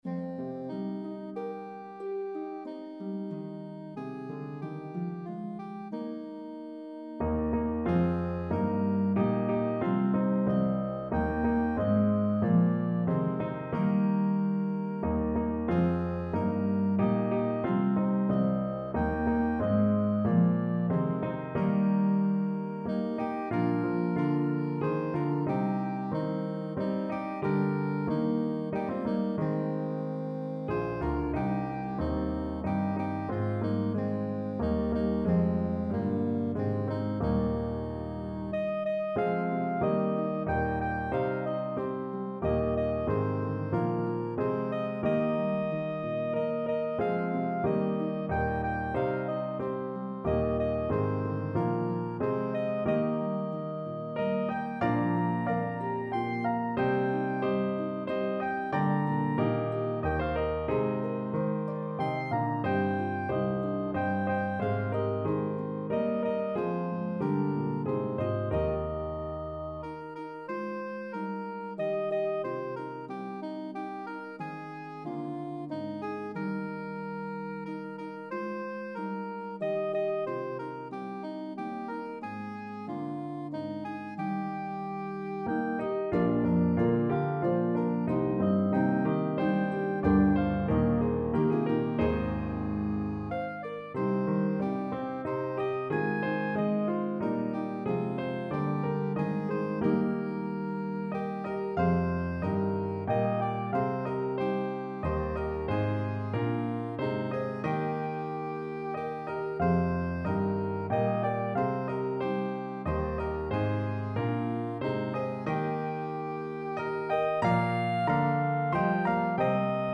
A meditative trio arrangement
pentatonic hymn tune